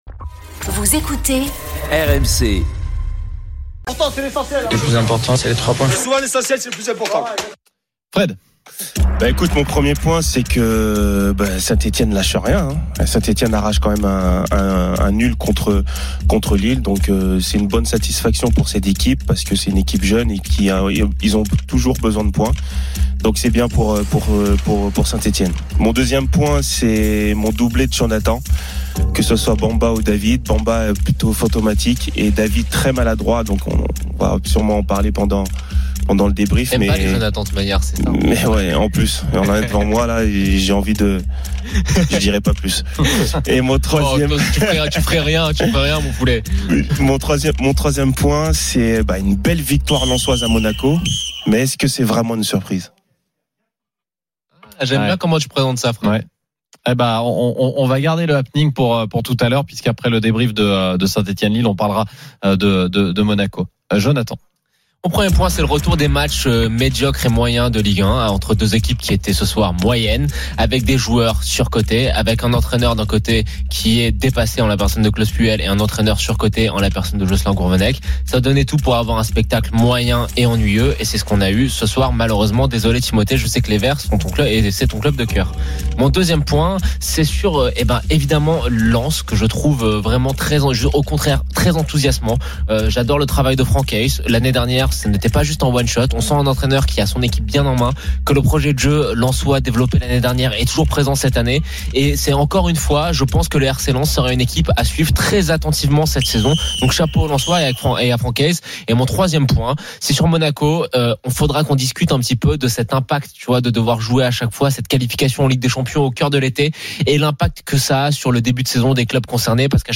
Chaque jour, écoutez le Best-of de l'Afterfoot, sur RMC la radio du Sport !
les réactions des joueurs et entraîneurs, les conférences de presse d'après-match et les débats animés entre supporters, experts de l'After et auditeurs.